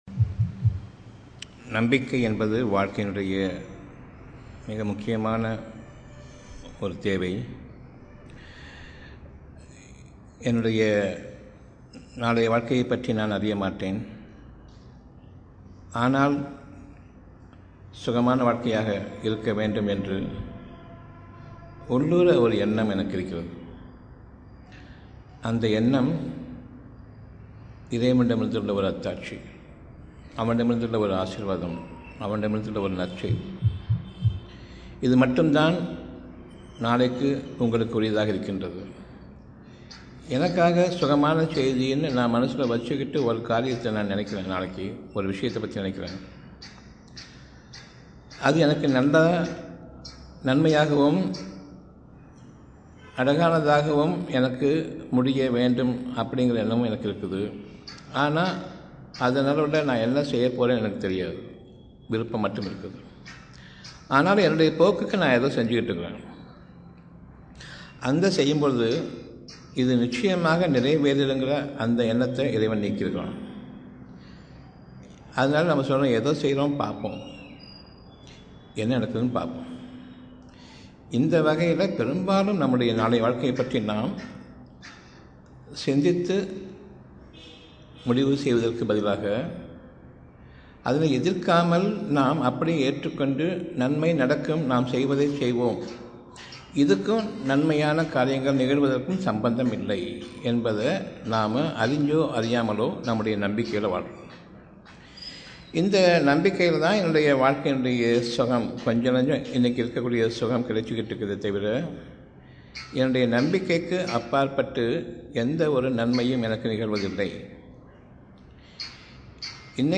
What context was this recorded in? Audio Venue St.John vestry anglo indian school